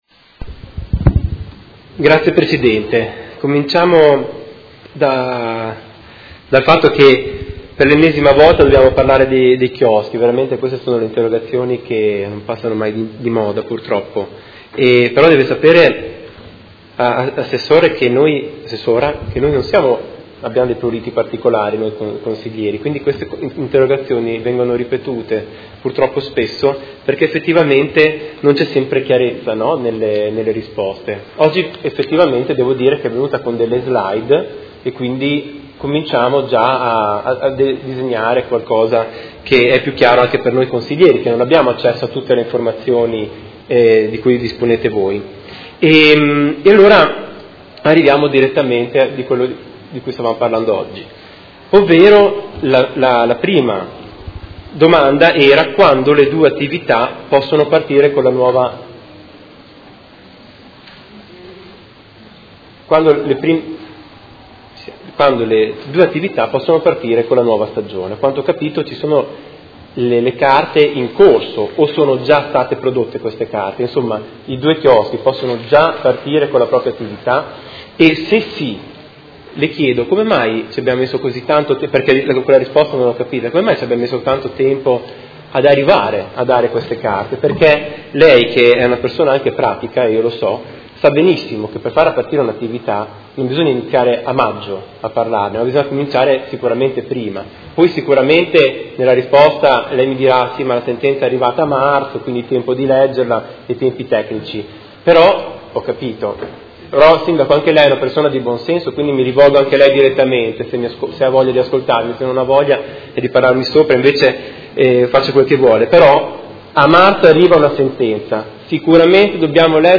Marco Chincarini — Sito Audio Consiglio Comunale
Seduta del 04/06/2018. Dibattito su interrogazione dei Consiglieri Stella e Chincarini (Art.1 MDP-Per Me Modena) avente per oggetto: Ripresa delle regolari attività estive dei chioschi al Parco delle Rimembranze: quando sarà possibile?